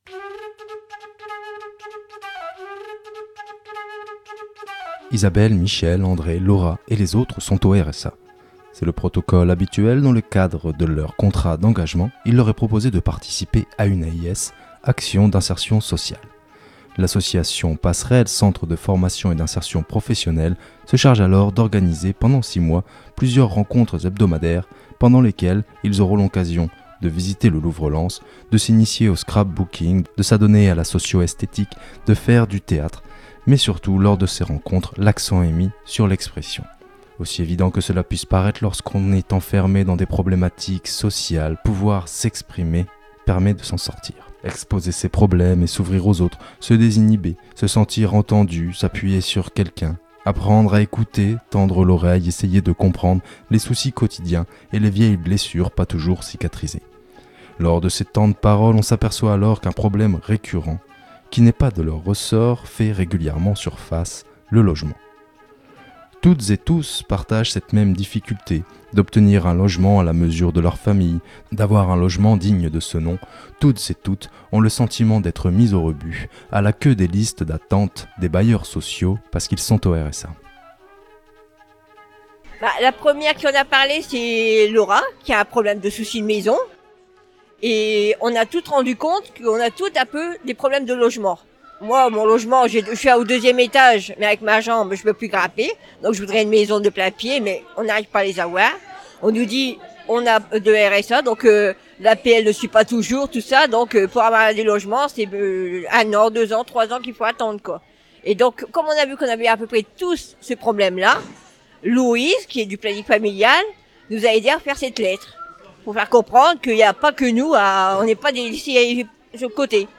Micros rebelles reportage
Micros rebelles a assisté à cette restitution théâtrale et a interrogé les acteurs.